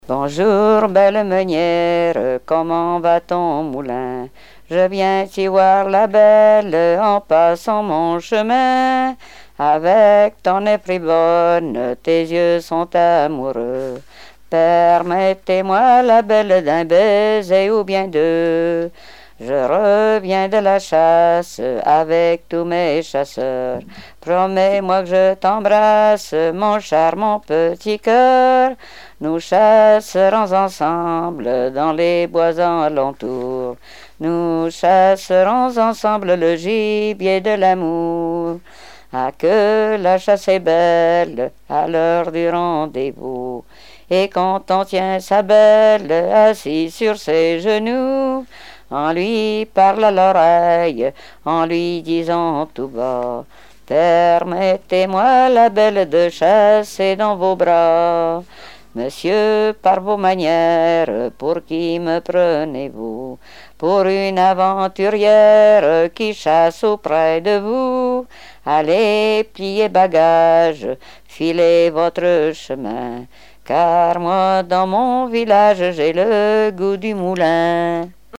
Genre énumérative
Répertoire de chansons traditionnelles et populaires
Pièce musicale inédite